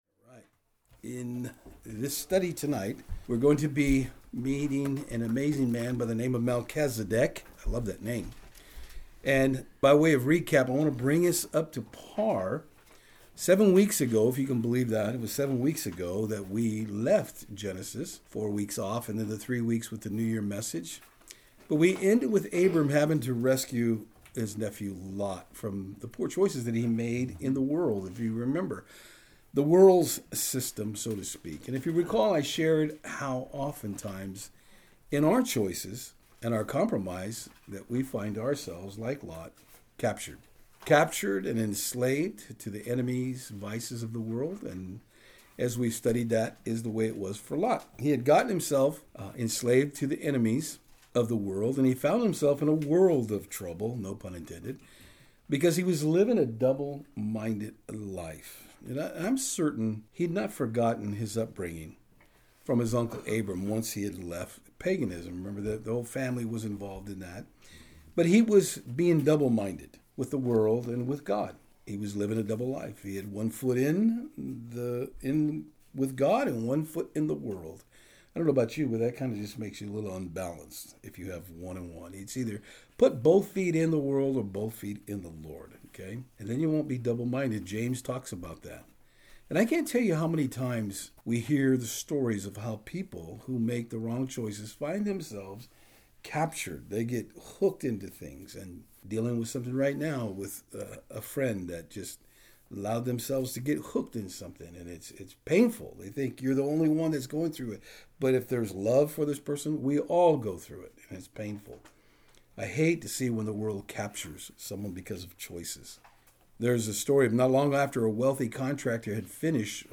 Service Type: Saturdays on Fort Hill